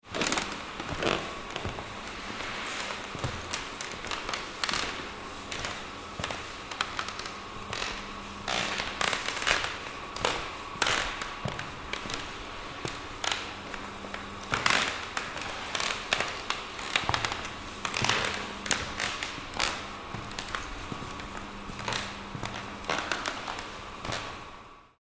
Walking on Old Squeaky Wooden Floor – Creaky Interior Footsteps Sound Effect
Authentic sound effect of footsteps walking on an old squeaky wooden floor. Each step features natural creaking of dry wooden planks under pressure. Perfect for horror scenes, abandoned interiors, historical settings, and Foley sound design.
Walking-on-old-squeaky-wooden-floor-creaky-interior-footsteps-sound-effect.mp3